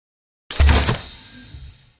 Among Us Door Opening Sound Effect Free Download
Among Us Door Opening